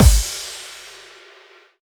VEC3 FX Reverbkicks 16.wav